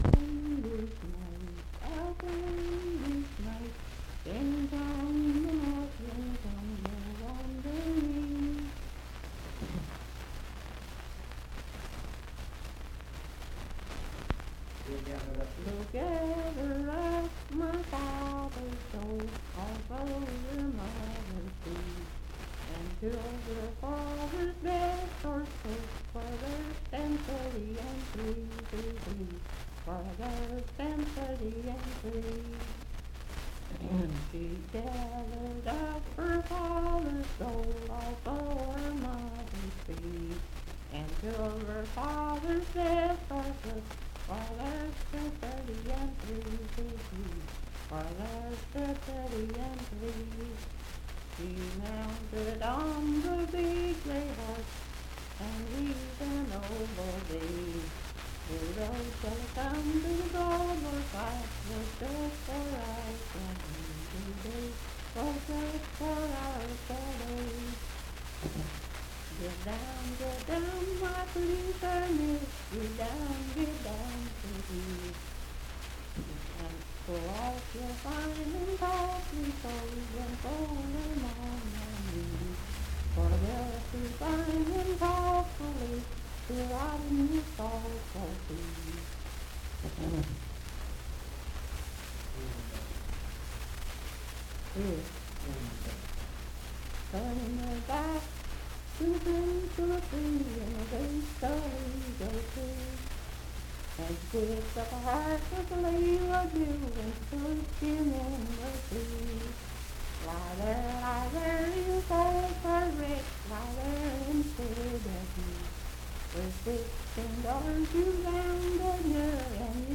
Unaccompanied vocal music
Verse-refrain 10(5w/R).
Voice (sung)
Moorefield (W. Va.), Hardy County (W. Va.)